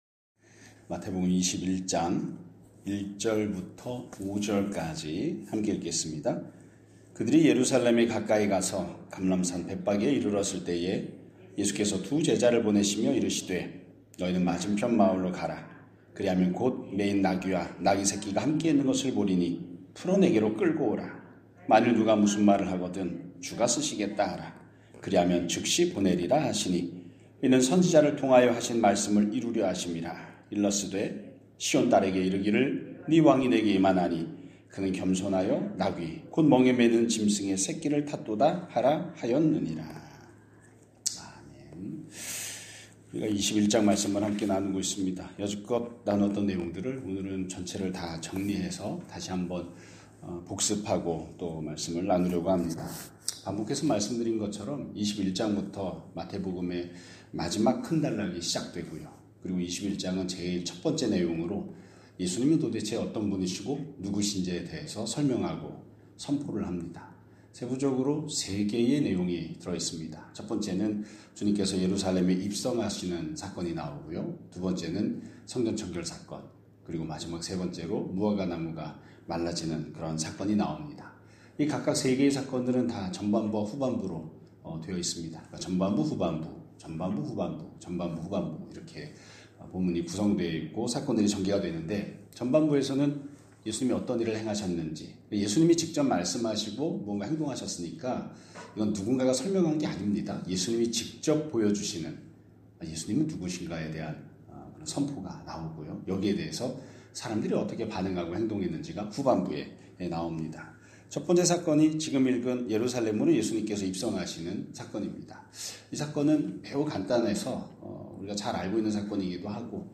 2026년 1월 29일 (목요일) <아침예배> 설교입니다.